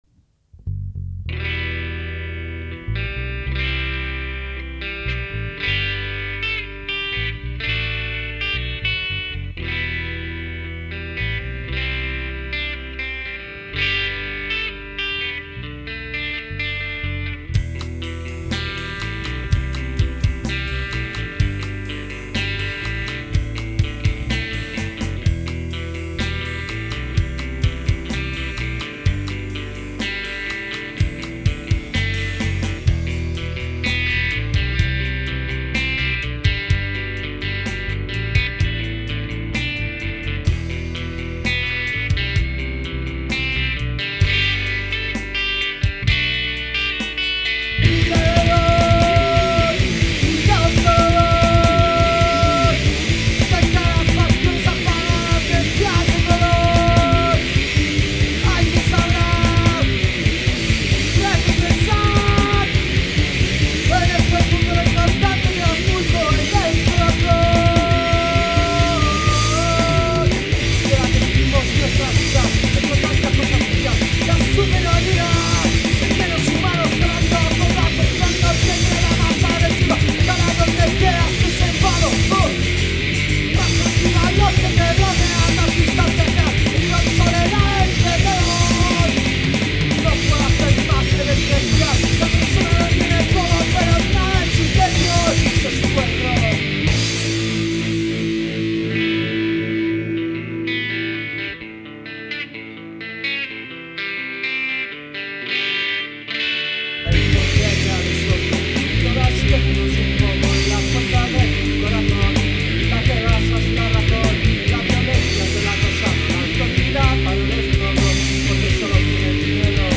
voz y guitarra
batería
punk